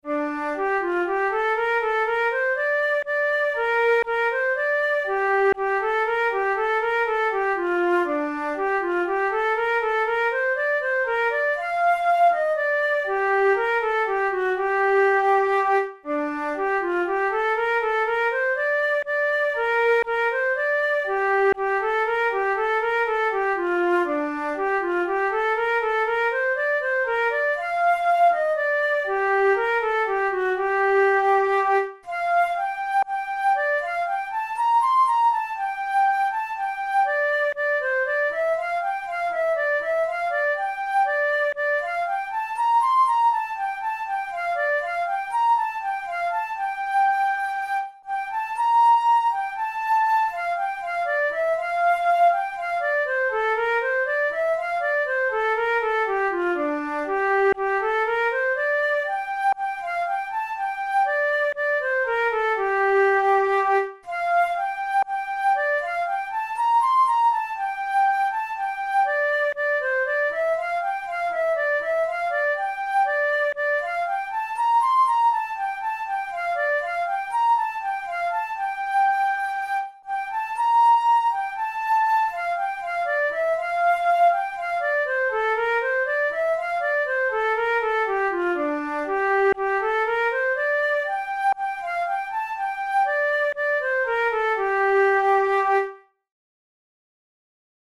Traditional Irish tune
Categories: Celtic Music Dance tunes Traditional/Folk Difficulty: easy